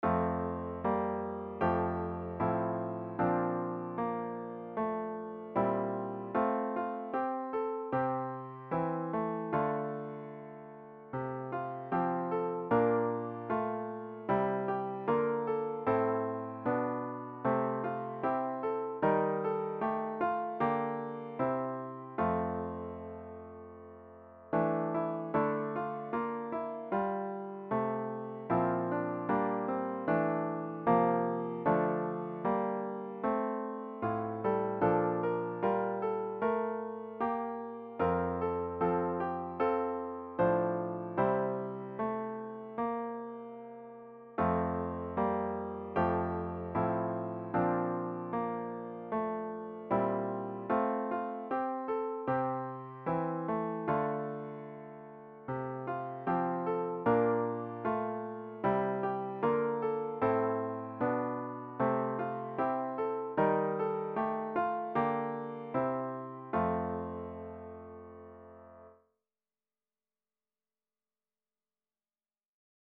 About the Hymn
The hymn should be performed at a confident♩= ca. 76.